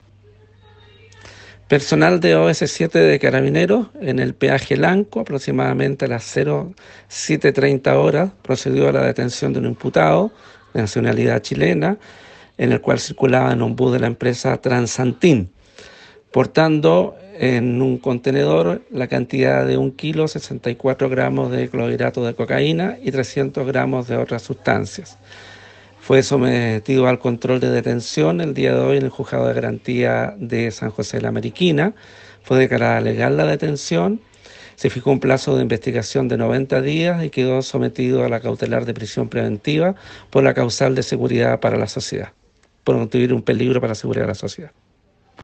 Fiscal Alejandro Ríos..